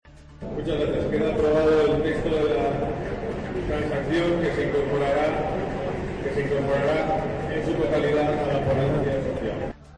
COPE tiene acceso al sonido de la votación en la que el PP aplaza su posicionamiento sobre los vientres de alquiler hasta escuchar a expertos